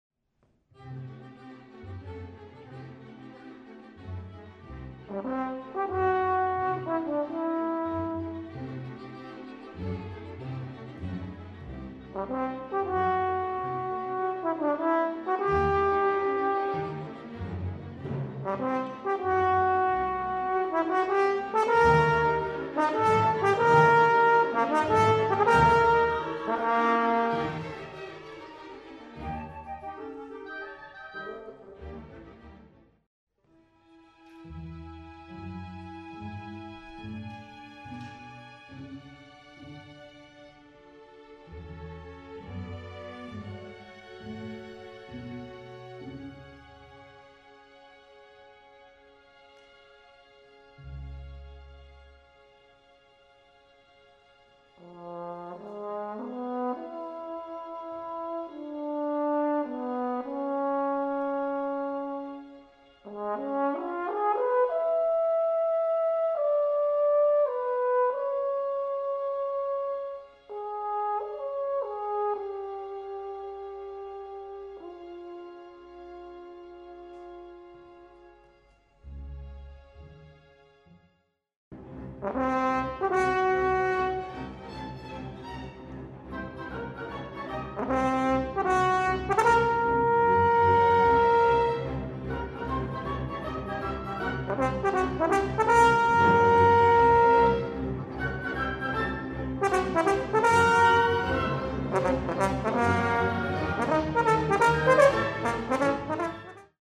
Concerto for Horn and Orchestra
Horn Soloist
[GASP] = Great Audio Sneak Preview from rehearsal on April 16, 2007 - 4 more rehearsals... (:-)
Horn_rehearsal_clip.mp3